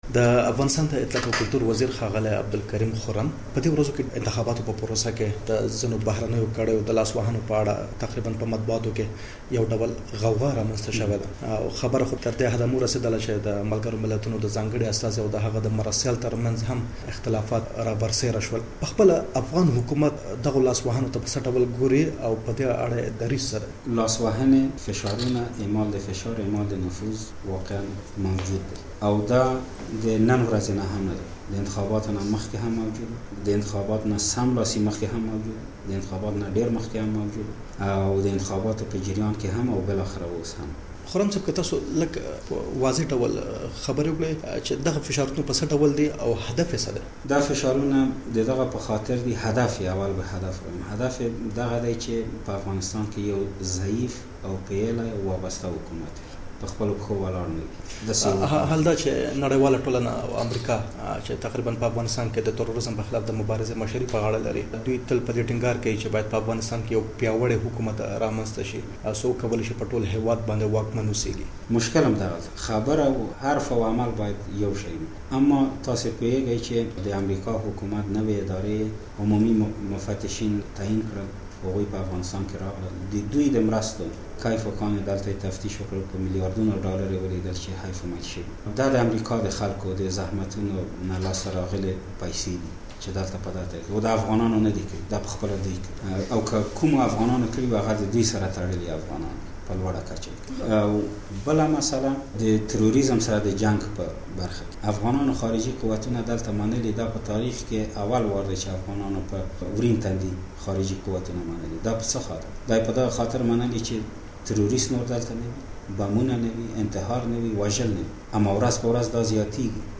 عبدالکریم خرم سره د ازادۍ راډیو مرکه